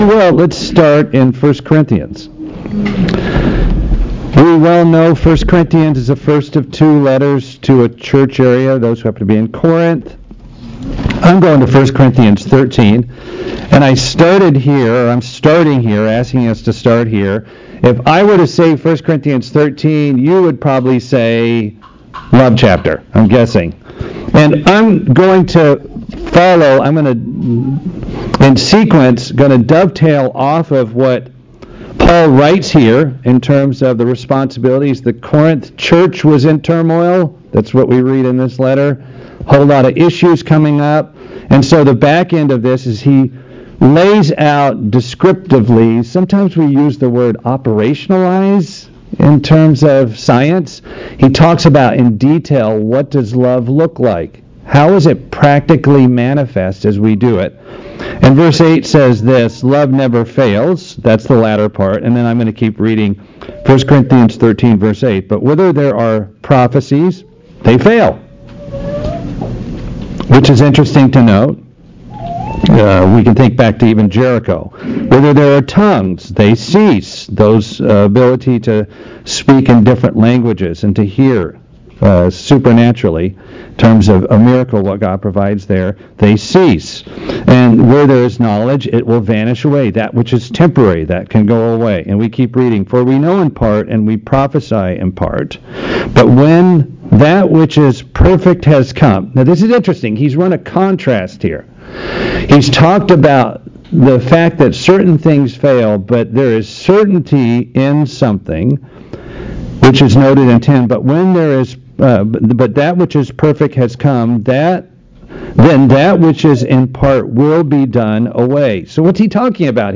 Have we been edified by what we're heard? This sermon gives us four important tips to help make those messages a part of our lives.